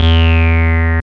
78.07 BASS.wav